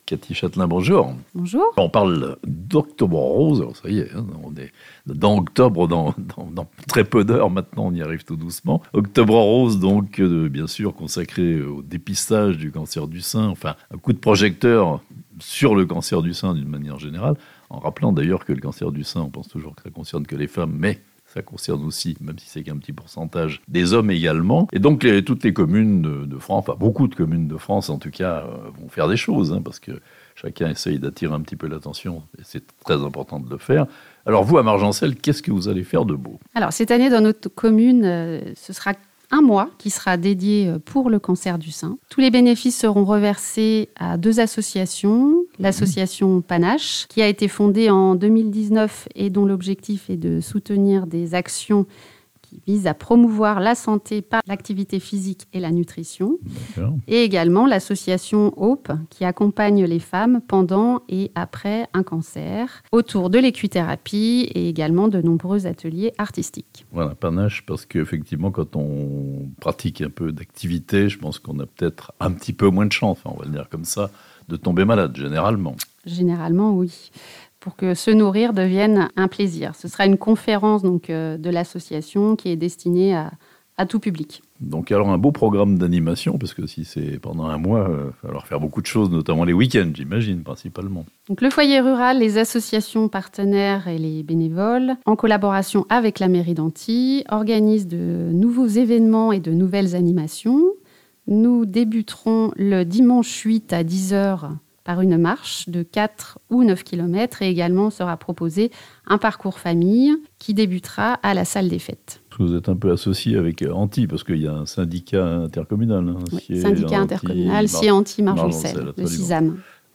De nombreuses animations à Margencel à l'occasion d'Octobre rose (interview)